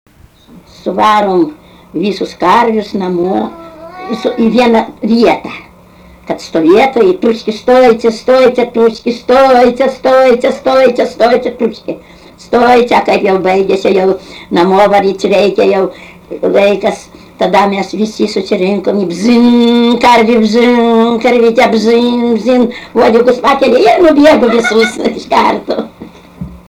smulkieji žanrai
Aukštadvaris
vokalinis
Su komentarais, yra ir baltarusių kalba